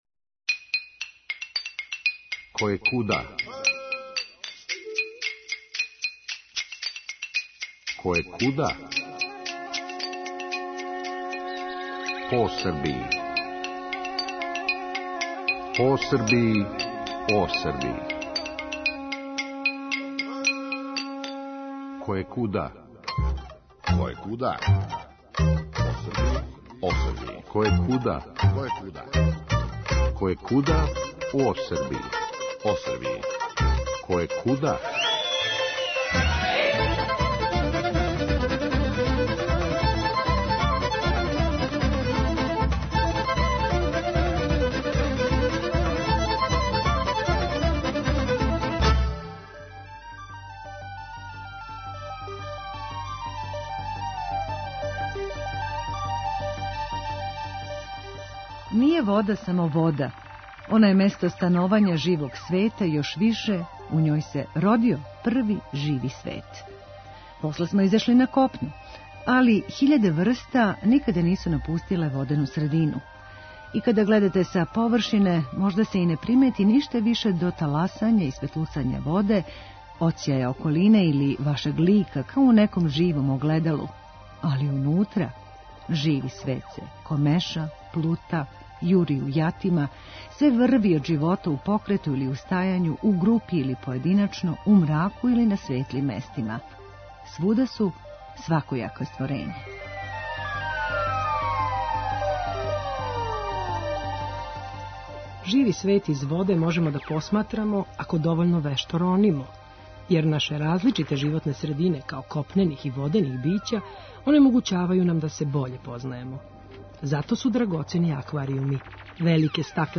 Емисија Радио Београда 1